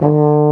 BRS BARI D2.wav